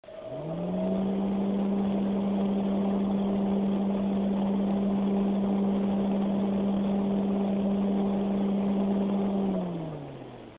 Acoustic Samples: Listen to this Heatsink!
The Gigabyte G-Power Pro heatsink weighs in at 50.9 dBA on the low side, and 71.9 dBA with the 25mm thick Everflow fan spinning at full speed.
frostytech acoustic sampling chamber
Low-Speed Recording.
With the fan dialed down to the slowest RPM setting it is effectively a lower noise heatsink.
aud_ghpdu21mf_lo.mp3